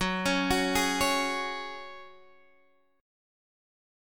F#sus2 chord